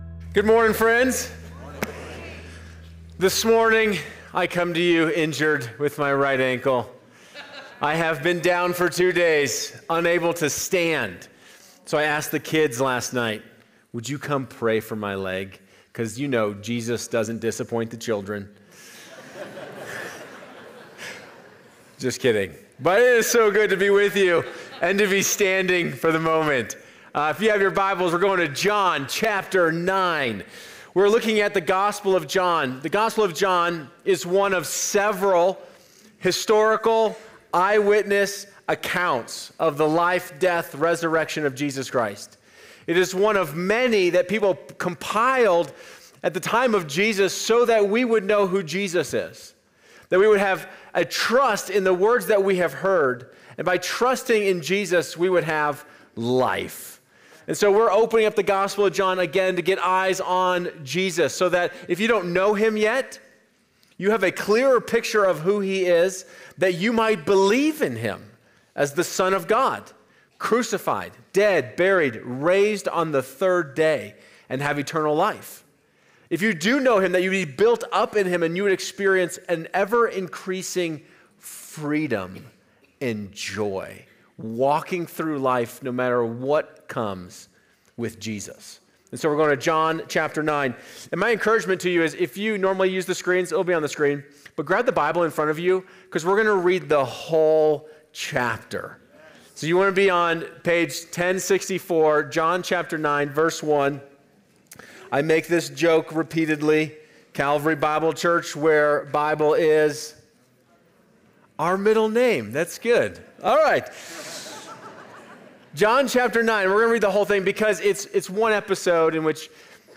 We hope this daily practice helps prepare your hearts as we celebrate Jesus’ first coming and look forward to his second.